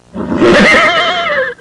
Horse (tired) Sound Effect
Download a high-quality horse (tired) sound effect.
horse-tired.mp3